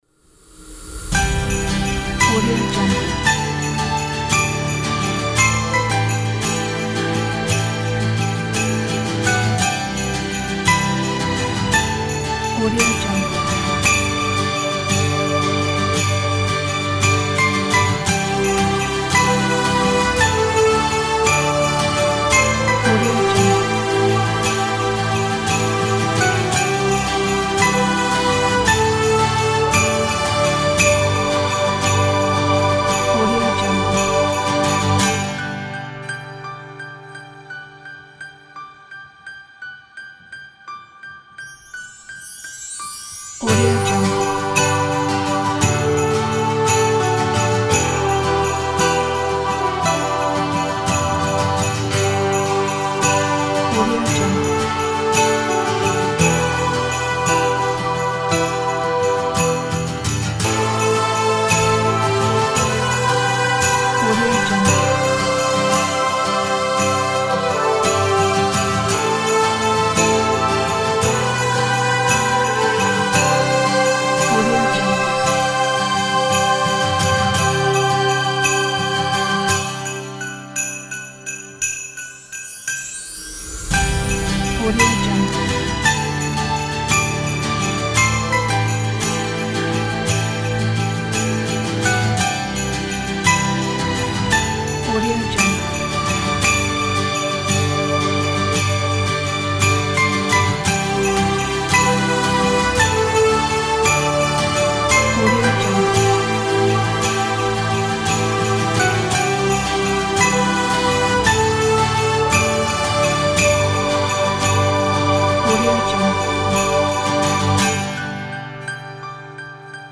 فولک، آکوستیک